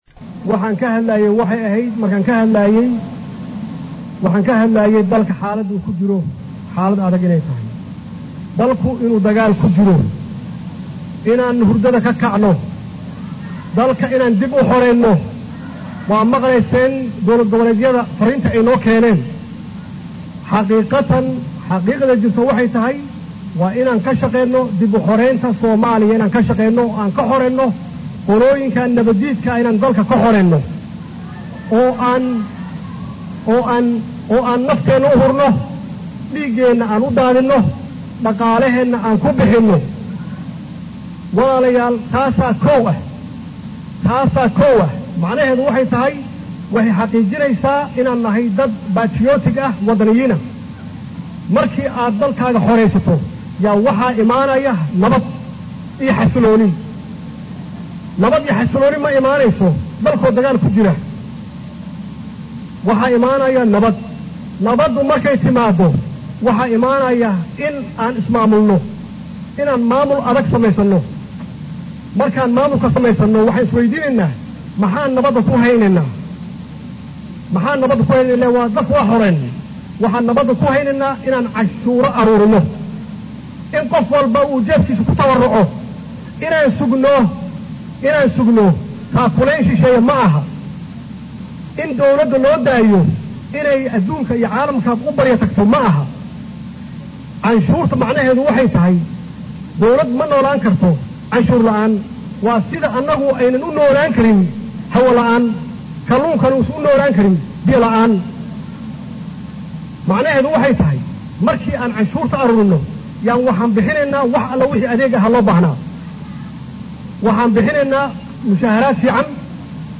Mudane Maxamed C/llaahi Maxamed Farmaajo Madaxweynaha Jamhuuriyadda Soomaaliya xili uu sheelay khudbad dhinacyo kala duwan taabanaysa dastuurina ah ka hor jeedinaayay munaasbadii lagu furayay kalfadhigii sedexaad ee Baarlamaanka Jamhuuriyadda Soomaaliya ayuu tilmaamay Madaxweyne Farmaajo in Dalku ku jiro dagaal kaasoo dowladda Federaalka Soomaaliya kula jirto maleeshiyaadka nabad iyo nolal diidka ah ee Al-shabaab kuwaas oo wali ku dhuumaalaysana shacabka dhexdiisa.